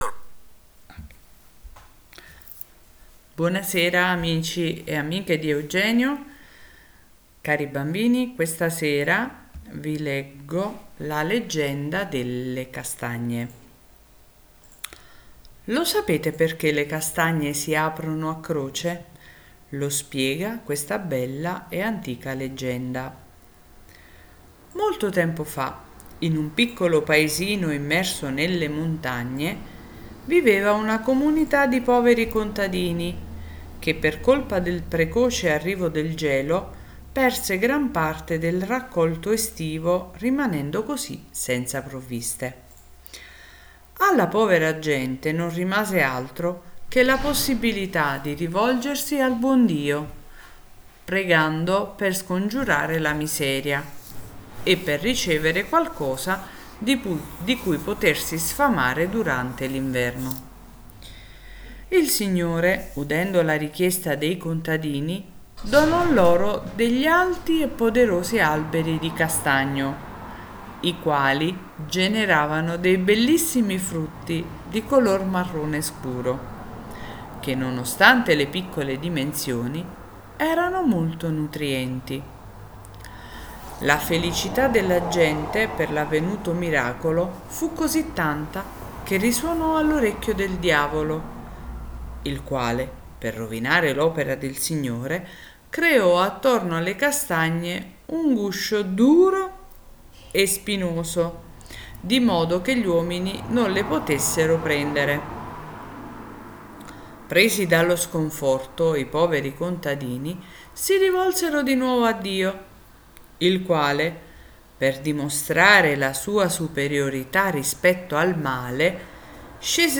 Ascolta la leggenda delle castagne